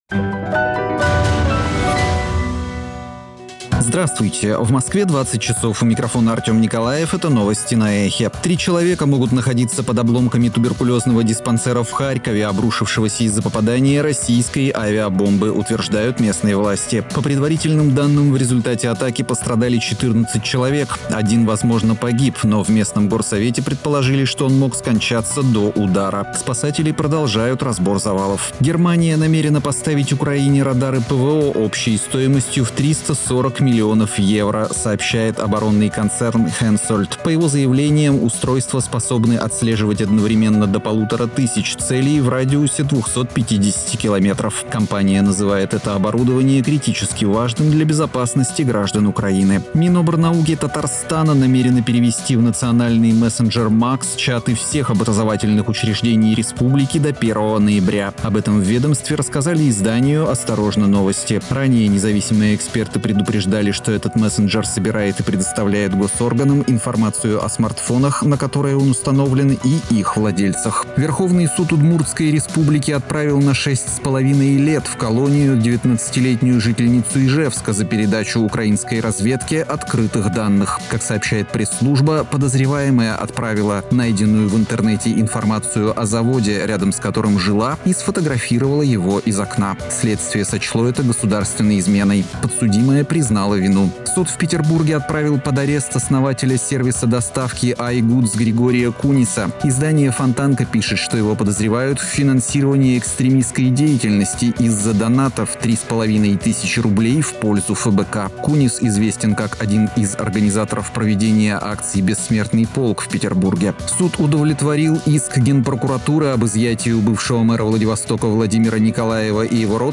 Слушайте свежий выпуск новостей «Эха»
Новости